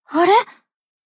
贡献 ） 分类:蔚蓝档案语音 协议:Copyright 您不可以覆盖此文件。
BA_V_Sumire_Battle_Damage_3.ogg